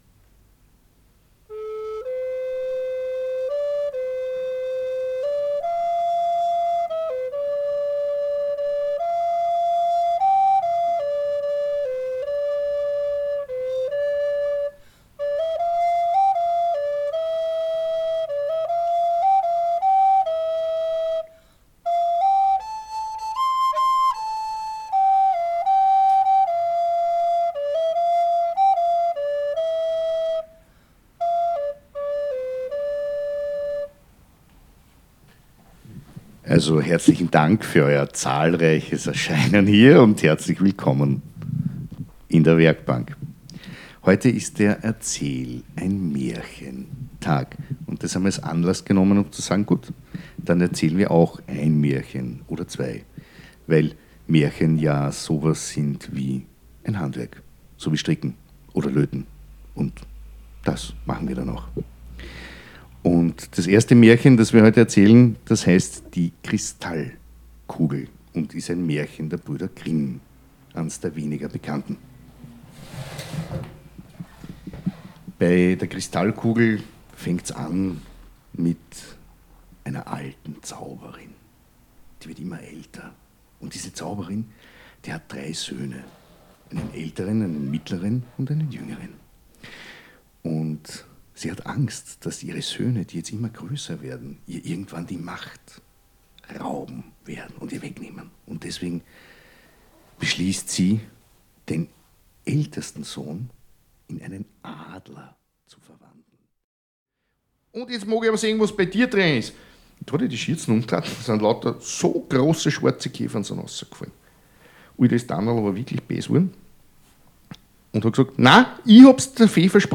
Hörbuch
Live-Aufnahme am 26 Feber anno 2015
Schlagworte Der sprechende Berg • Der Trommler • Erzählwerkstatt • Flötenspiel • Kinder • Kristallkugel • Krokodilshöhle • Lindwurmprinz • Live - Erzählen